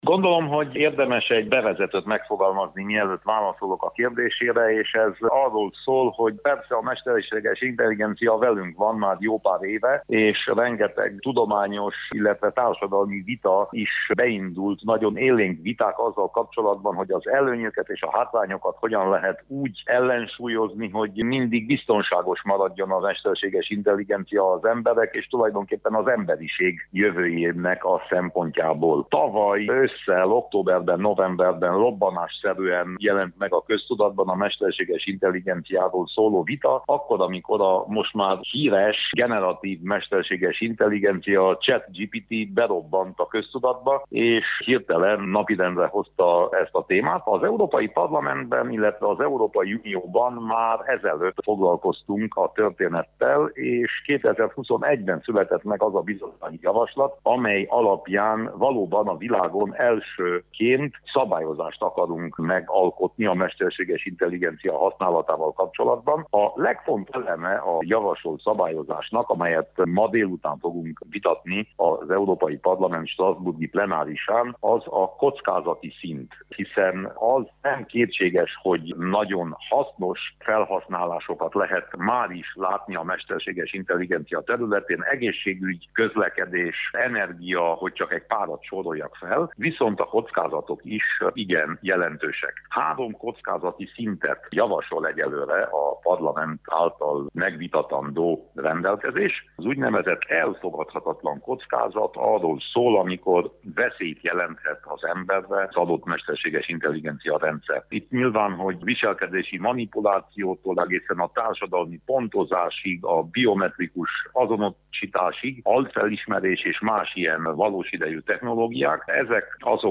emelte ki Winkler Gyula európai parlamenti képviselő